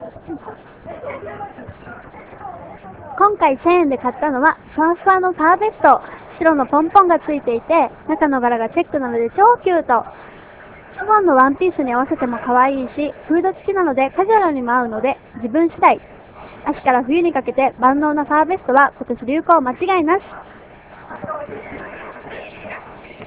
早速原宿竹下通りでお買い物です
リポート等の音声はスタジオ録音ではなく現地録りになりますので他の人の声などの雑音が入っていたりしますがご了承願います。